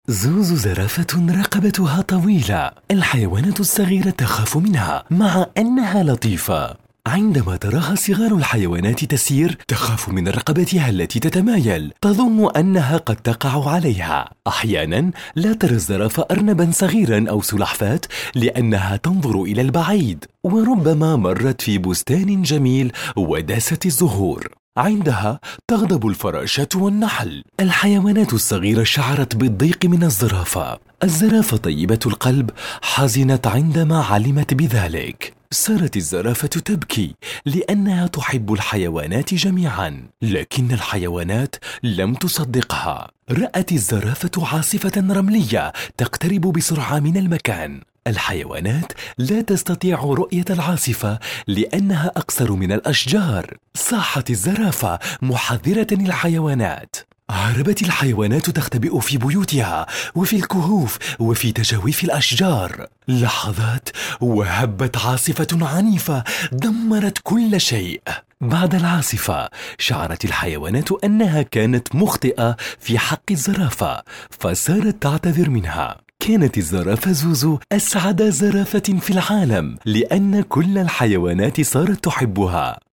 Voix Off Arabe Comédien Chanteur Narrateur Animateur Radio Tv Team Building
Sprechprobe: eLearning (Muttersprache):